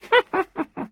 Sound / Minecraft / mob / witch / ambient1.ogg
should be correct audio levels.